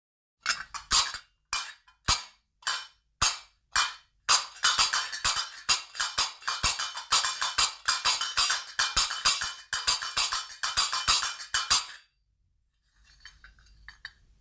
鉄カスタネット(カルカバ)大
モロッコのグナワ音楽に欠かせない鉄カスタネット別名「カルカバ・カルカベ」Qarqabaといいます。通常左右の手に一個ずつセットし、2個で独特なリズムを奏でます。
素材： 鉄